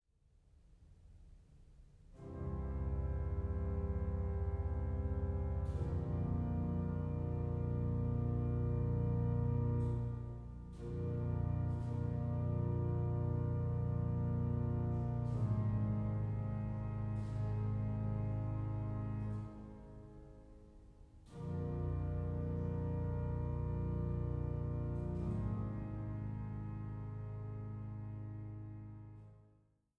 Bariton
Orgel